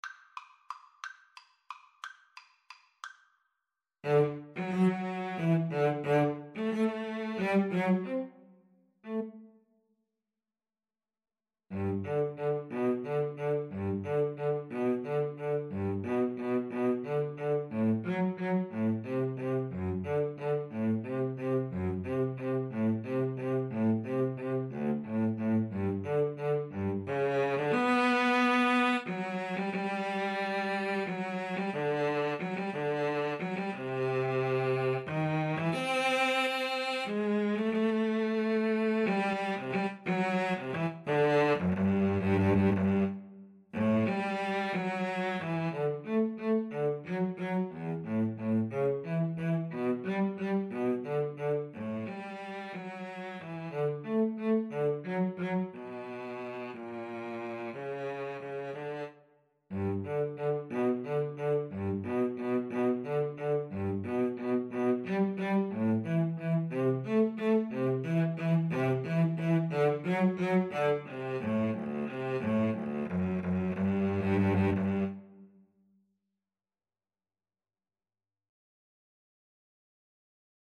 Free Sheet music for Cello Duet
G major (Sounding Pitch) (View more G major Music for Cello Duet )
~ = 180 Tempo di Valse
3/4 (View more 3/4 Music)